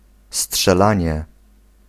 Ääntäminen
France: IPA: /tiʁ/